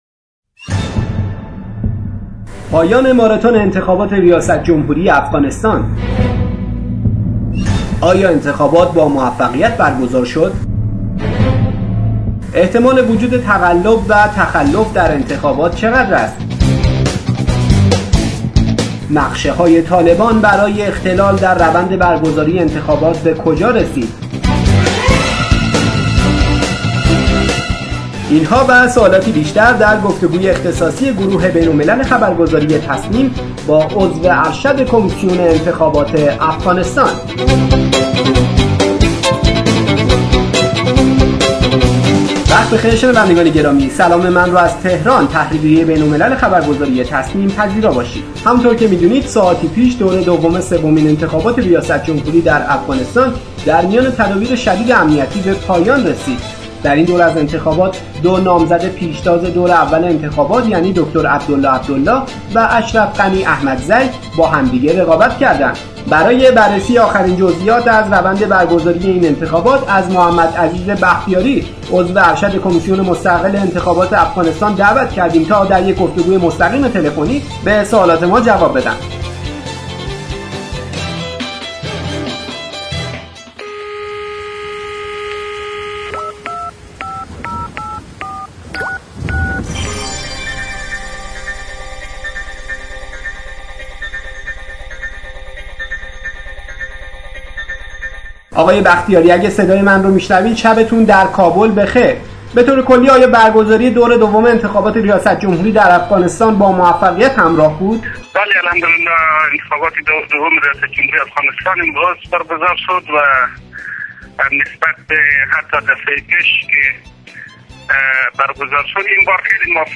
برای بررسی آخرین جزئیات از روند برگزاری این انتخابات از محمد عزیز بختیاری، عضو ارشد کمیسیون مستقل انتخابات افغانستان دعوت کردیم تا در یک گفتگوی مستقیم تلفنی به سوالات خبرنگار بین الملل تسنیم، پاسخ بدهد.